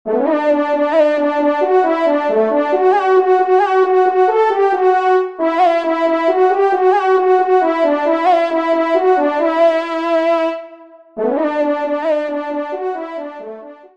Genre : Musique Religieuse pour  Quatre Trompes ou Cors
Pupitre 1°Trompe